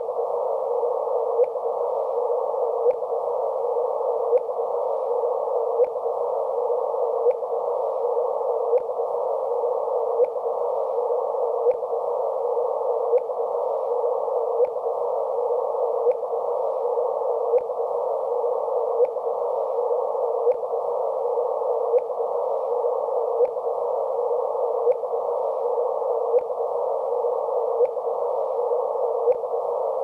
WORLDS FIRST GRAVITY WAVE RINGTONE!